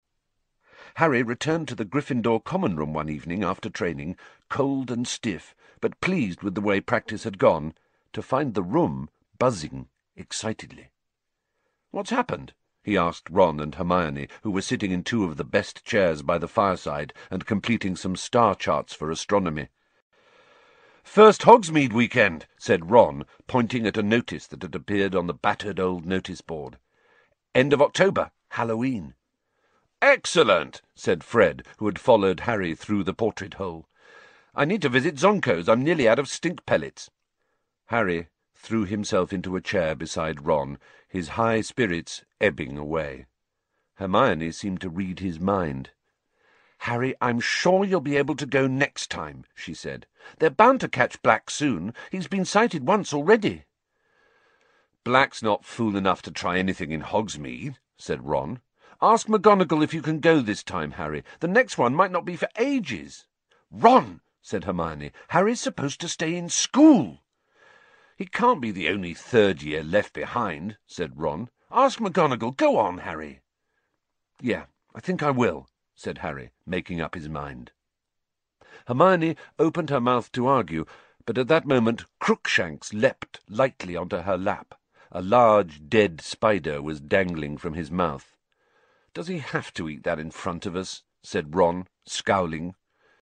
有声读物《哈利波特与阿兹卡班的囚徒》第151期:胖夫人逃走(5)